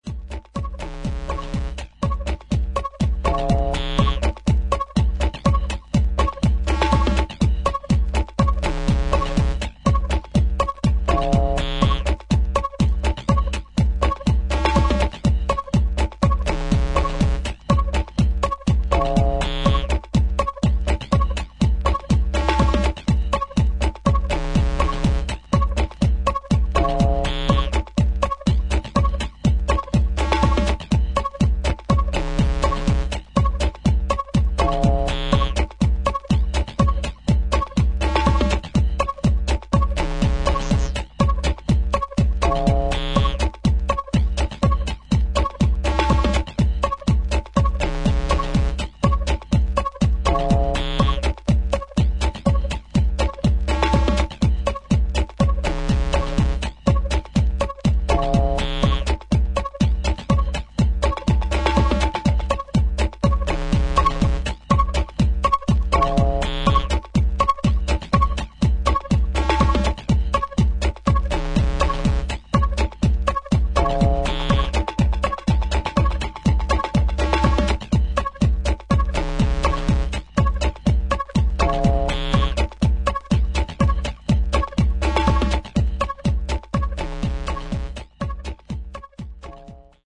低音の音圧の野太さにも驚かされる一枚。